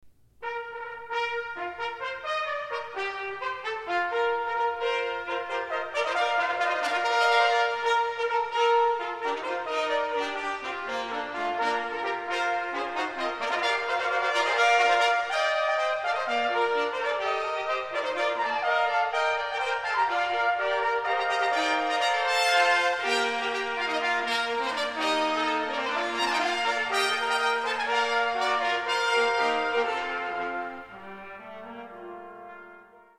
Multiple Trumpets
A festive work for 6 B-flat or C trumpets.